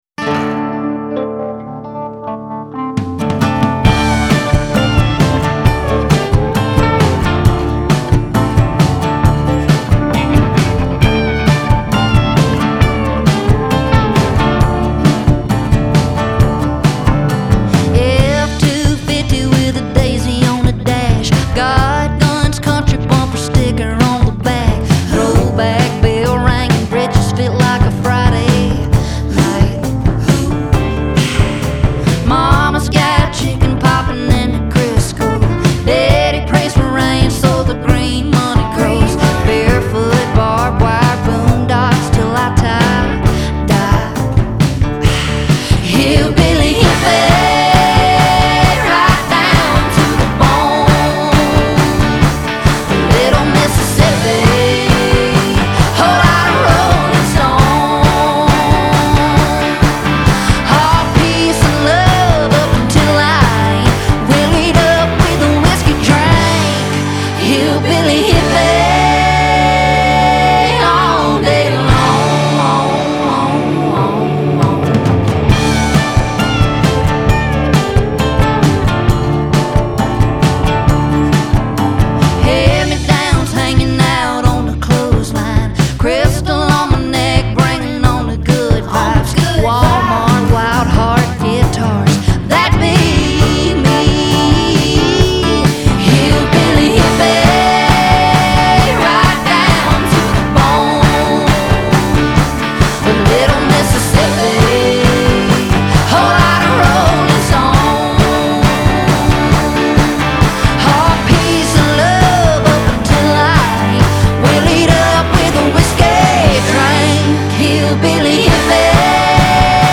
Country, Southern Rock, New Country, Americana